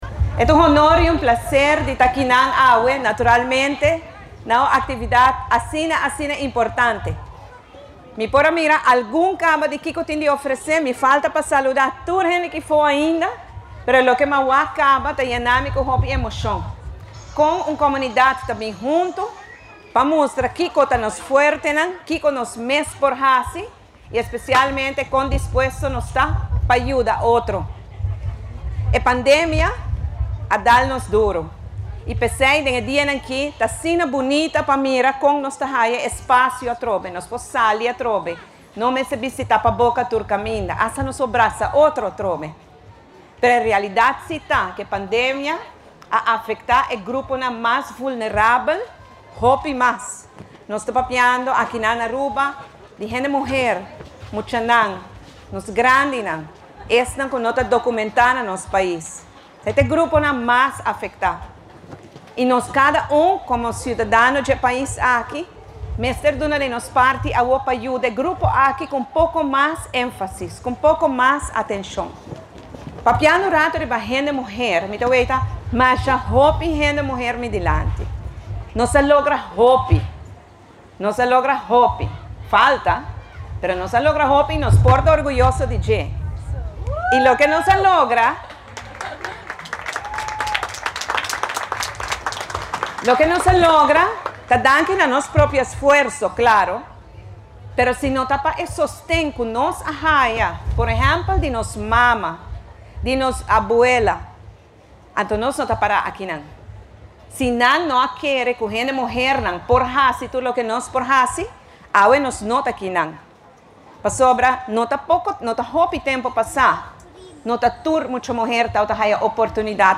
ORANJESTAD – Diadomingo a tuma luga un “Expo – Feria” na Centro di Bario Dakota den conexion cu Dia Internacional di Hende Muhe cu ta e siman aki 8 di maart.
Prome Minister Evelyn Wever – Croes a hiba palabra expresando gran felicidad na e ocasion pa mira comunidad bini hunto y sostene otro.